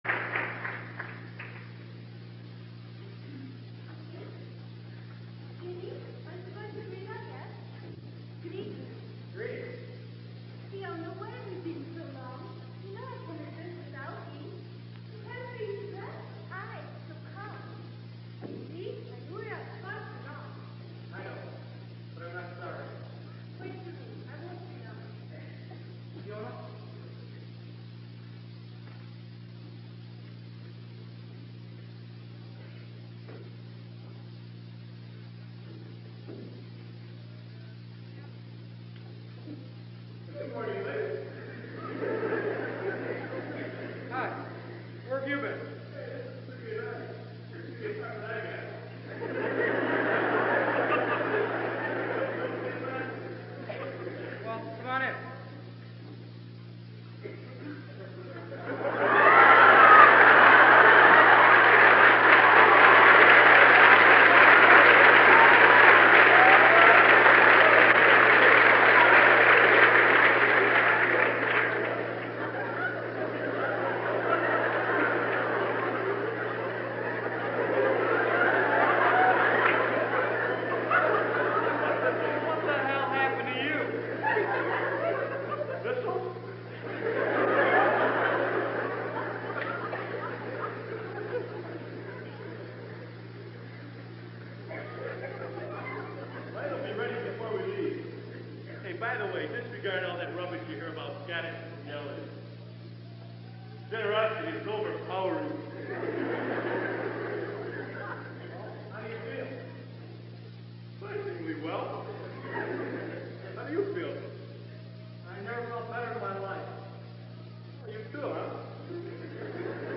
From a drawer somewhere, stored away for all those years on an old and nearly forgotten reel-to-reel tape in miraculously good condition, the ghost of Brigadoon has arisen to speak to us.
All musical selections include the surrounding dialogue.